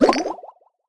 barkeep_throw_01.wav